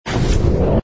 engine_bw_fighter_start.wav